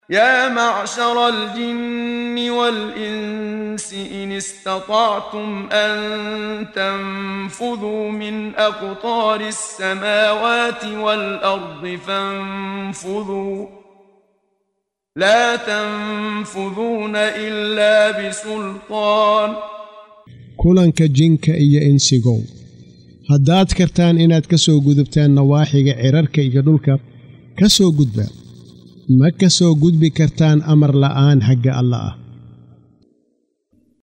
Waa Akhrin Codeed Af Soomaali ah ee Macaanida Suuradda Ar-Raxmaan ( Naxariistaha ) oo u kala Qaybsan Aayado ahaan ayna la Socoto Akhrinta Qaariga Sheekh Muxammad Siddiiq Al-Manshaawi.